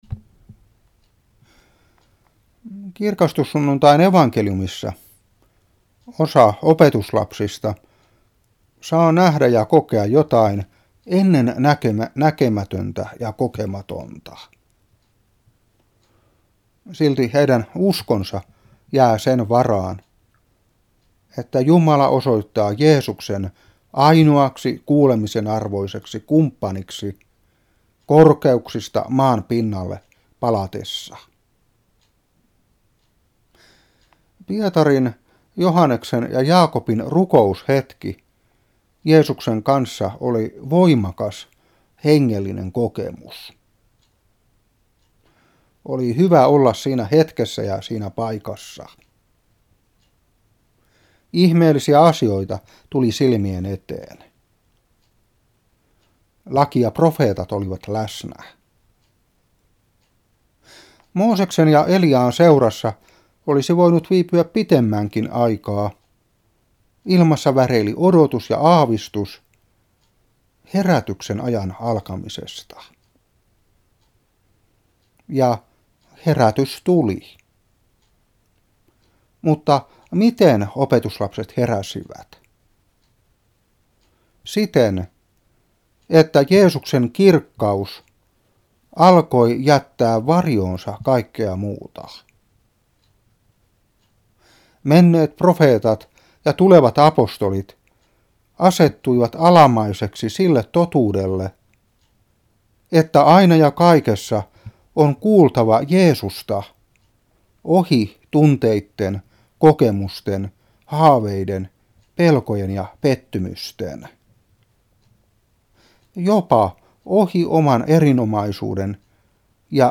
Seurapuhe 2009-7.